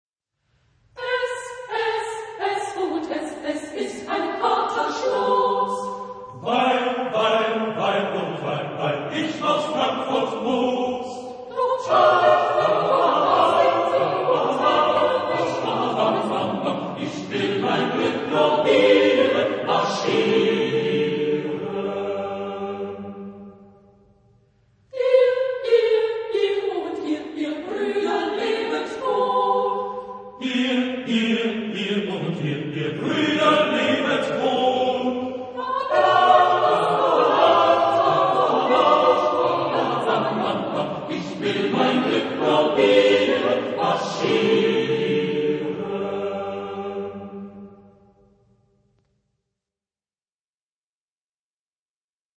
Genre-Stil-Form: Volkslied ; Liedsatz ; weltlich
Chorgattung: SATB  (4 gemischter Chor Stimmen )
Tonart(en): G-Dur